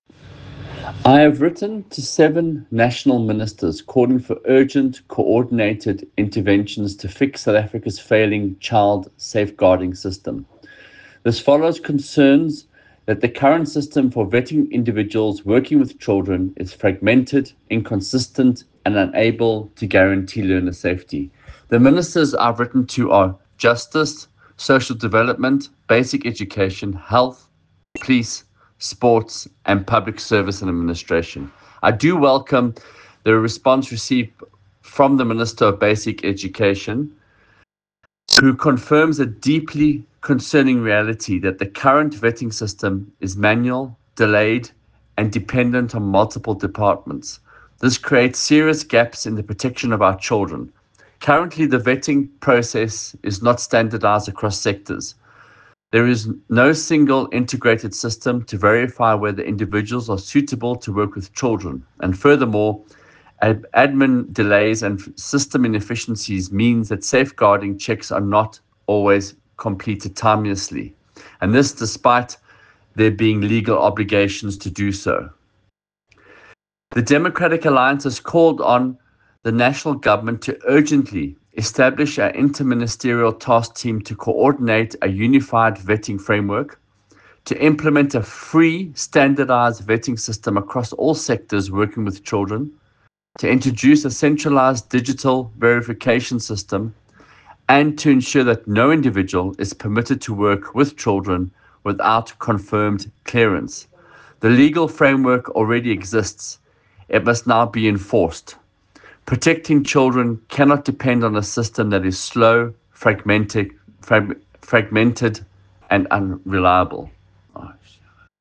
Note to Editors: Attached please find a soundbite in English by DA MPL, Michael Waters